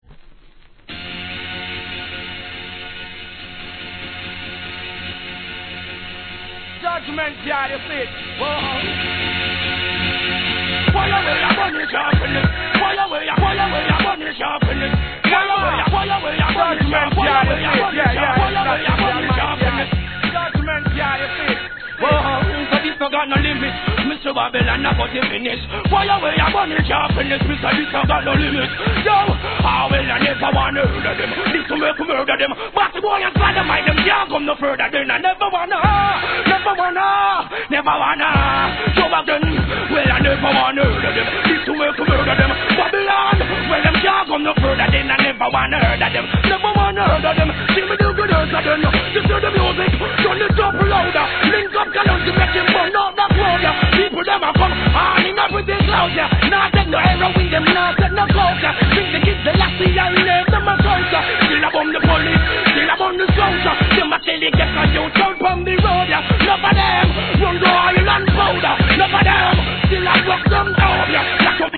REGGAE
'OOs HIP HOPでのREMIX集!!